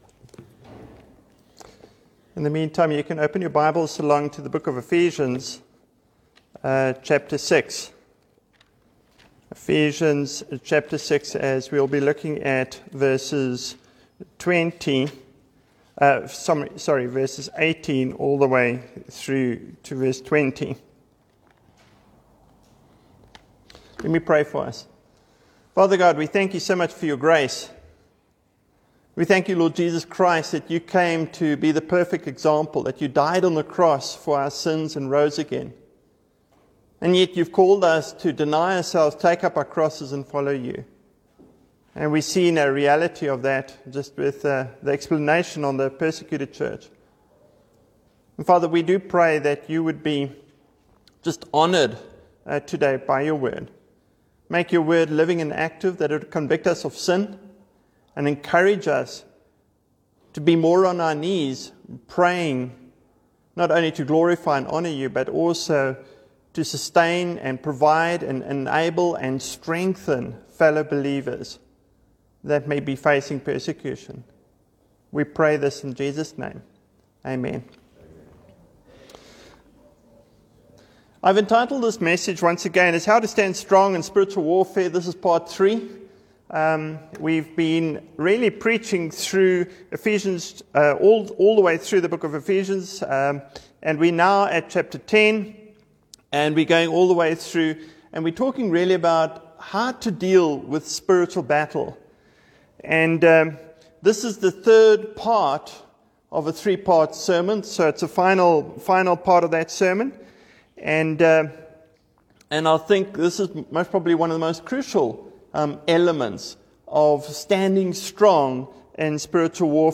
28-34 Service Type: Morning Service